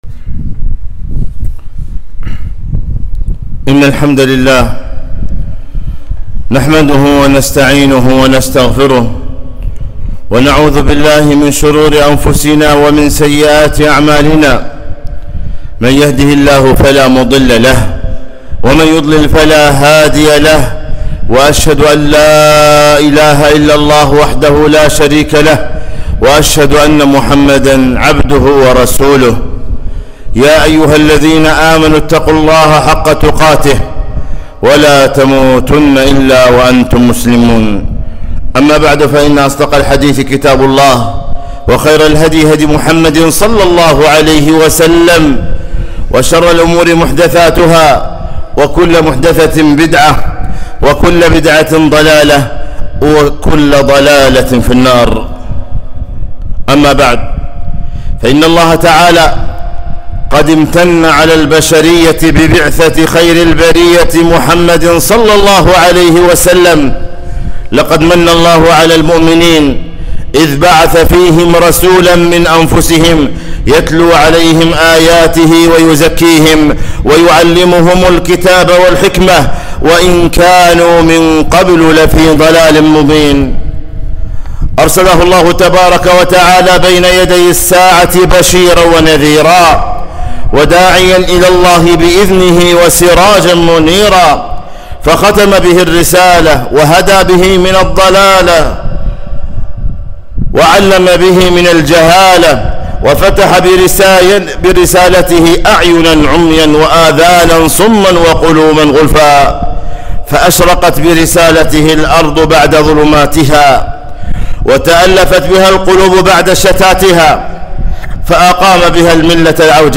خطبة - ( إلا تنصروه فقد نصره الله)